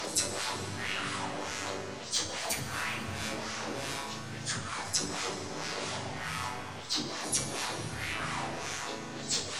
• Laser Abyssal Background 100 bpm.wav
Laser_Abyssal_Background_100_bpm__Q6H.wav